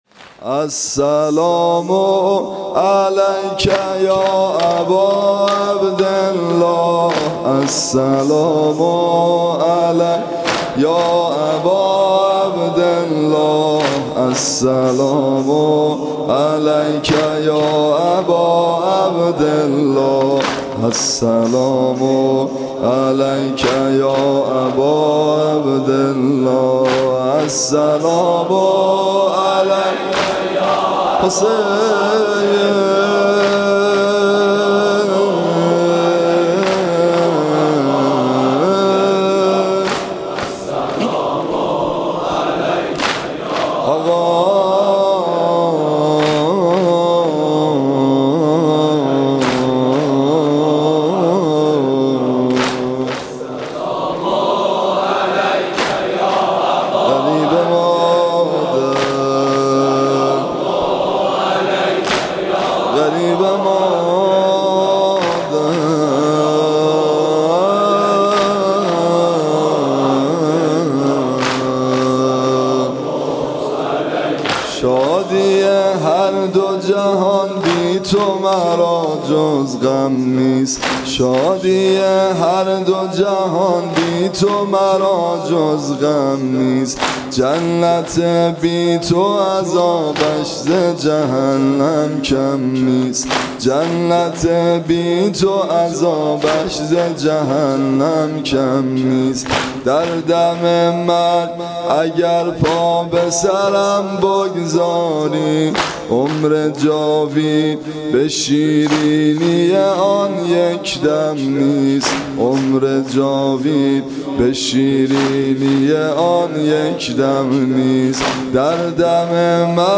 صوت مراسم شب دوم محرم ۱۴۳۷ هیئت ابن الرضا(ع) ذیلاً می‌آید: